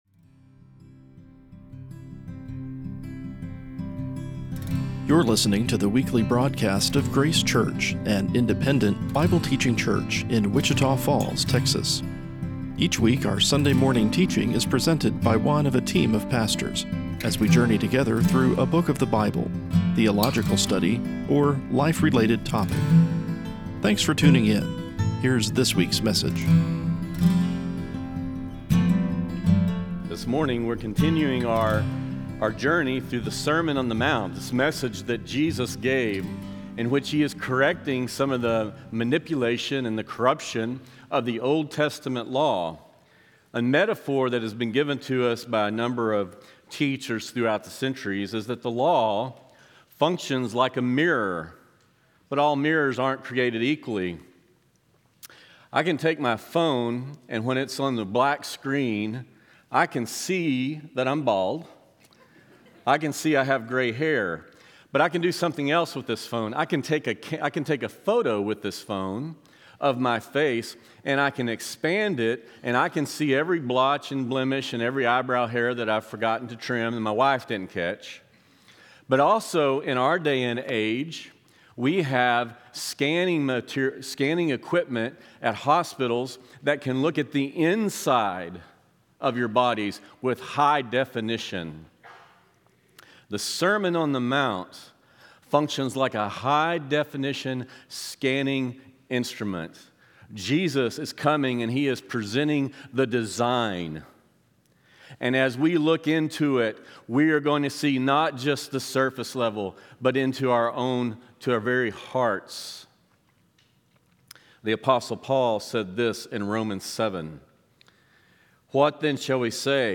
Our Sunday morning study of the “Sermon on the Mount” preached by Jesus, as recorded in the Gospel of Matthew. Discover the unexpected things He said about the nature of His Kingdom and why it is good news for those who dwell with Him.